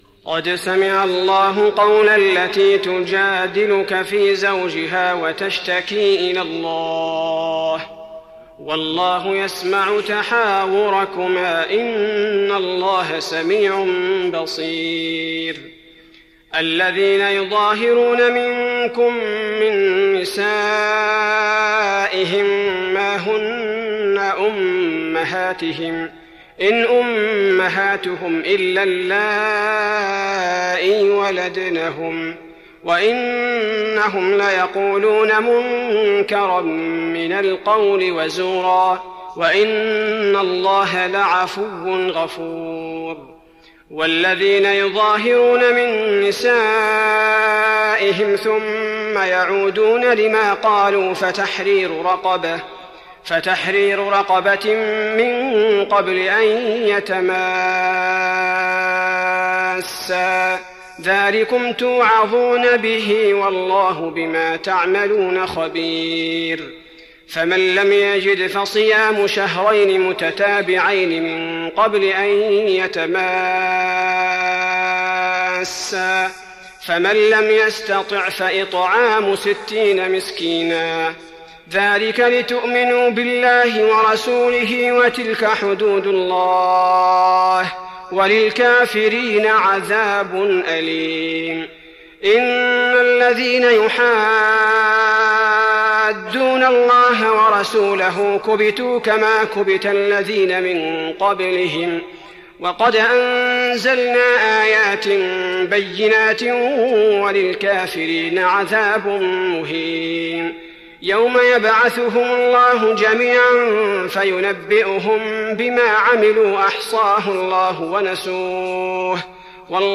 تراويح رمضان 1415هـ من سورة المجادلة الى سورة الصف Taraweeh Ramadan 1415H from Surah Al-Mujaadila to Surah As-Saff > تراويح الحرم النبوي عام 1415 🕌 > التراويح - تلاوات الحرمين